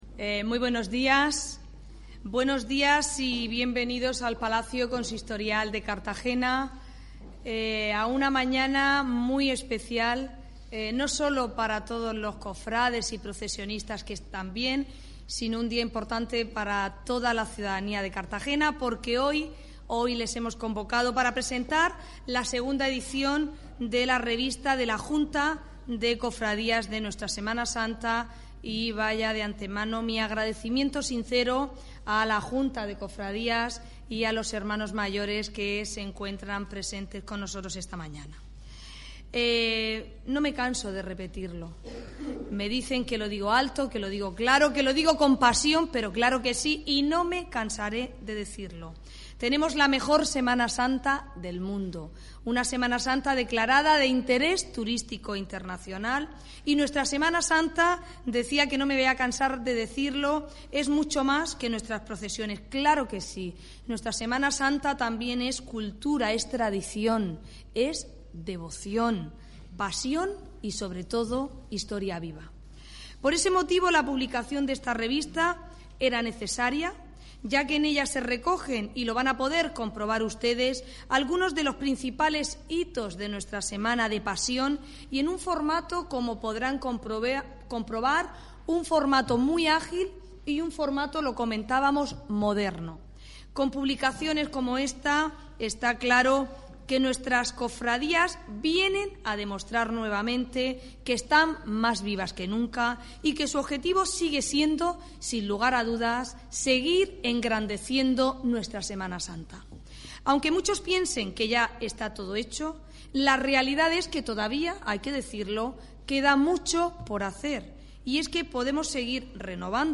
El acto tendr� lugar a las 11 horas en la Sala de Recepciones del Palacio Consistorial
Audio: Presentaci�n de la revista de Semana Santa de la Junta de Cofrad�as (MP3 - 7,06 MB)